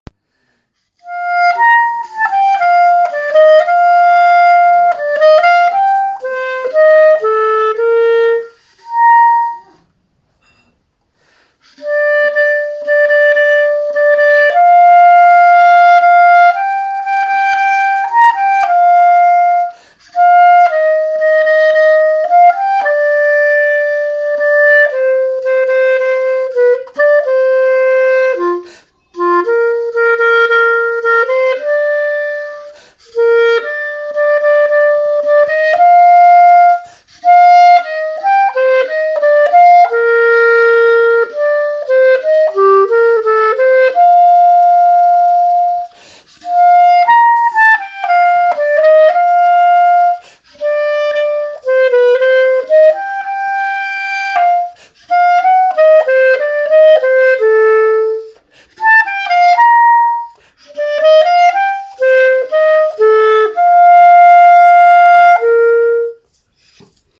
Ci-dessous une autre version instrumentale de la chanson. Cette fois, ce n’est pas du piano mais de la flûte. Contrairement au fichier audio précédent, la musique est jouée du début jusqu’à la fin de la partition.
musique-de-la-chanson-loin-du-reve-jouee-a-la-flute.mp3